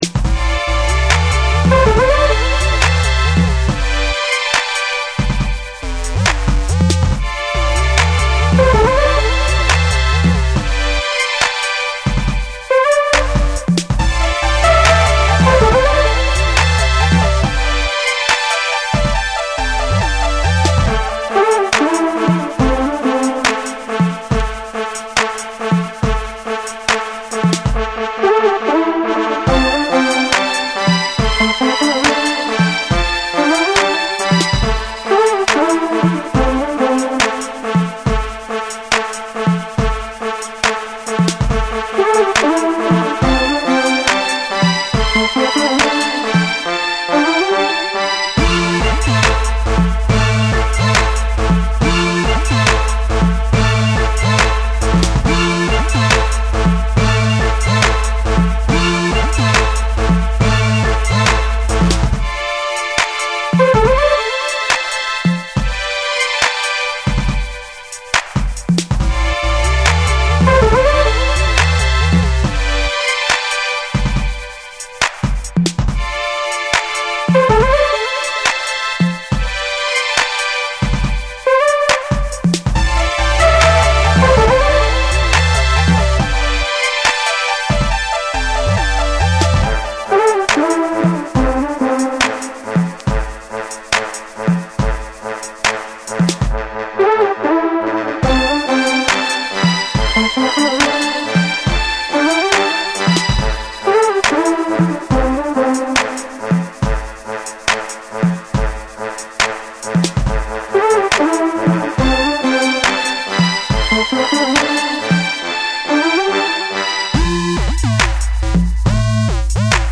クラシカルな要素を取り入れた華やかなシンセワークと軽快なリズムが印象的なダブステップ
BREAKBEATS / DUBSTEP